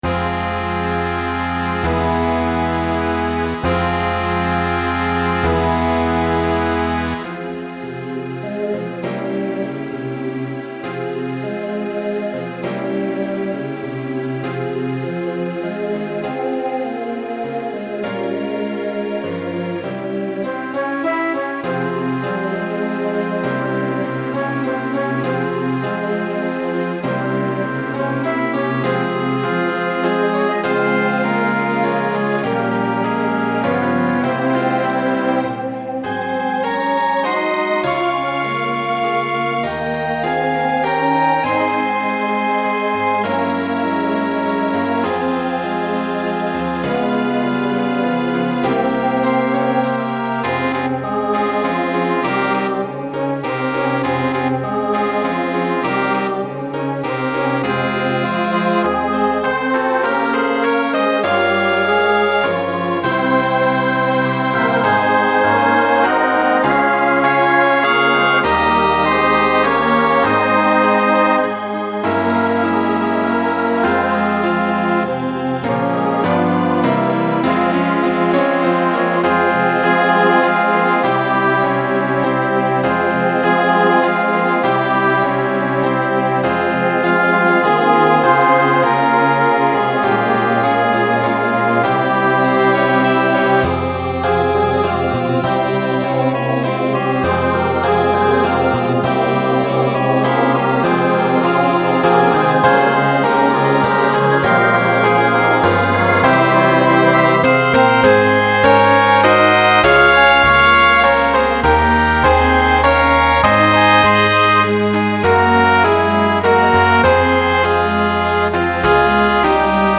Voicing SATB Instrumental combo Genre Broadway/Film
Function Ballad